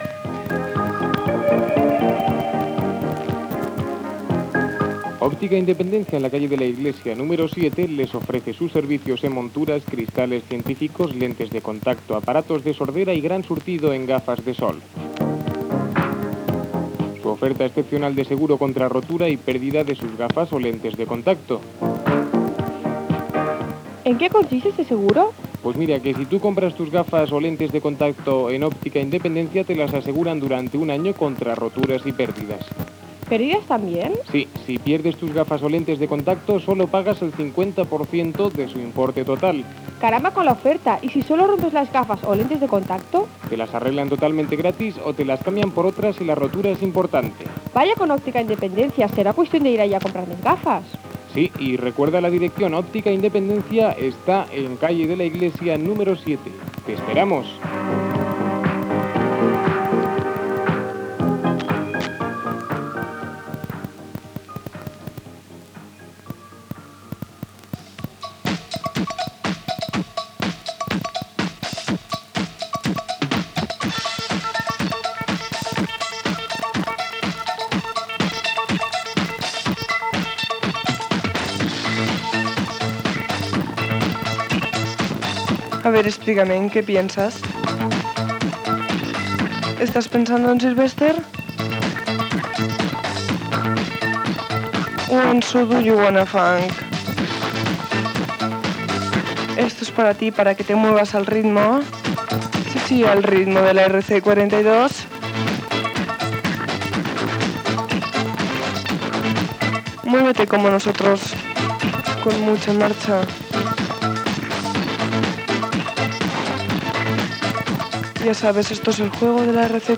Publicitat, presentació d'un tema musical.
Musical